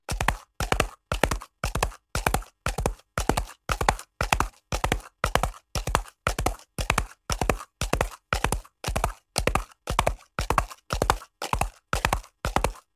horse_gallop_concrete.ogg